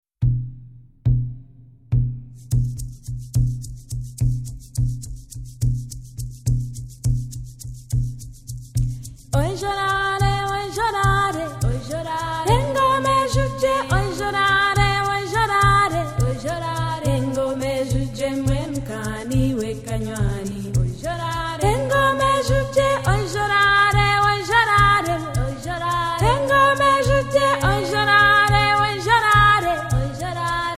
female voice, yodeling, ullulation, handclapping (engalo)